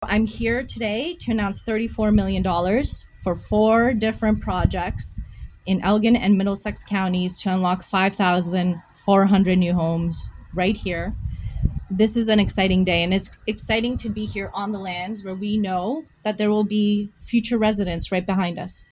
In an announcement Wednesday morning in Shedden, the Township of Southwold is the recipient of almost $28 million in provincial funding.
Ontario Infrastructure Minister Kinga Surma was on hand at the Shedden Fire Station for the announcement.